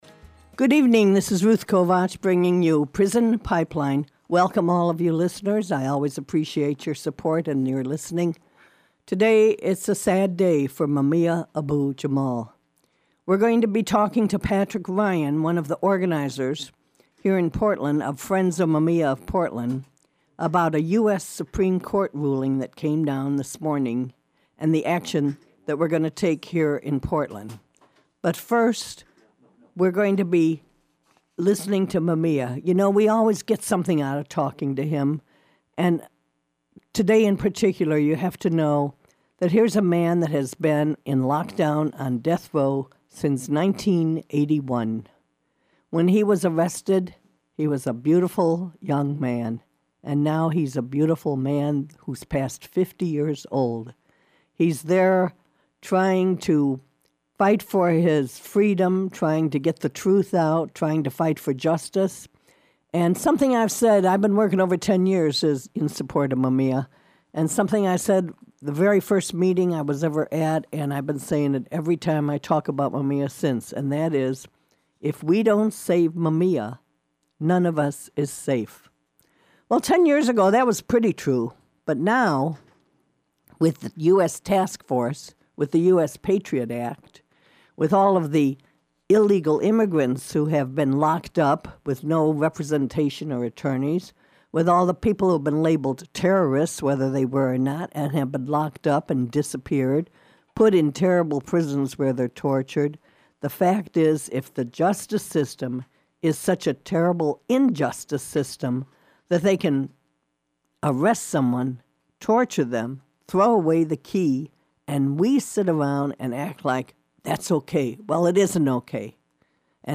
KBOO Radio reports on the US Supreme Court's rejection of Mumia Abu-Jamal's Appeal